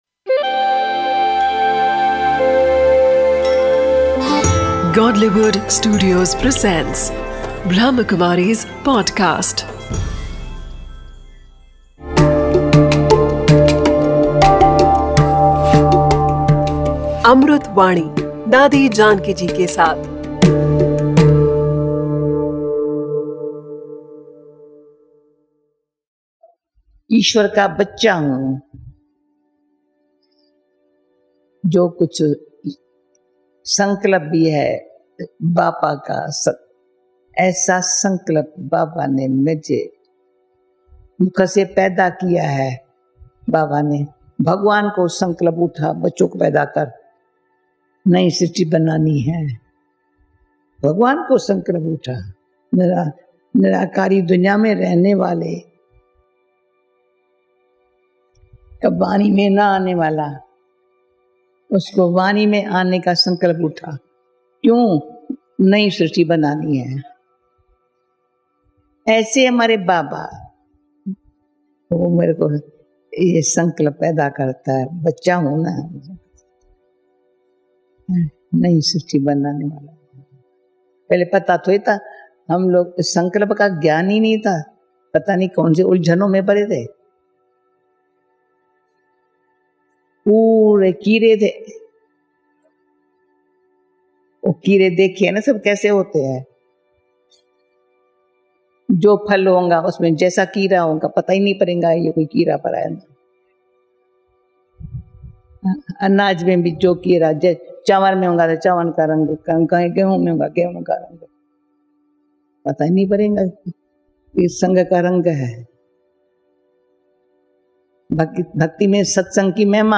'Amrut Vani' is a collection of invaluable speeches